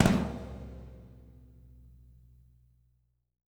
-TOM 2N   -R.wav